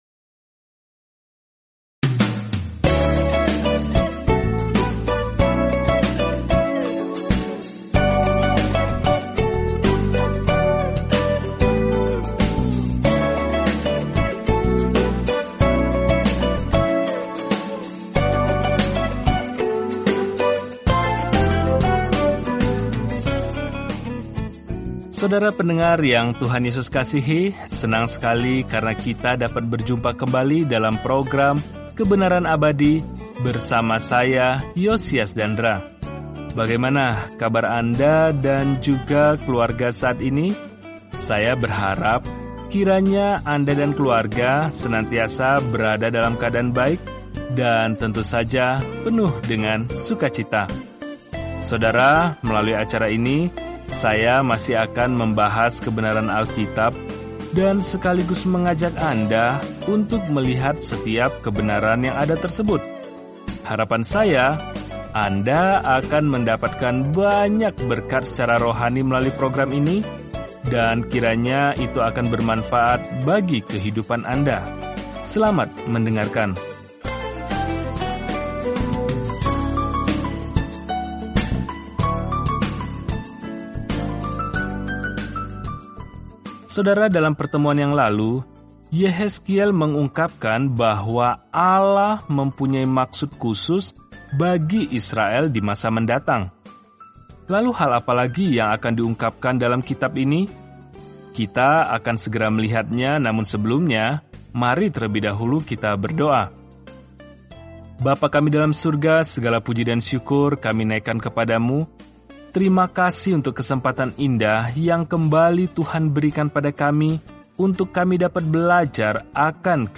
Firman Tuhan, Alkitab Yehezkiel 38:3-16 Hari 22 Mulai Rencana ini Hari 24 Tentang Rencana ini Orang-orang tidak mau mendengarkan peringatan Yehezkiel untuk kembali kepada Tuhan, jadi dia malah memerankan perumpamaan apokaliptik, dan itu menusuk hati orang-orang. Jelajahi Yehezkiel setiap hari sambil mendengarkan pelajaran audio dan membaca ayat-ayat tertentu dari firman Tuhan.